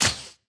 Media:ArcherQueen_base_atk_1.wav 攻击音效 atk 初级和经典及以上形态攻击音效
ArcherQueen_baby_atk_1.wav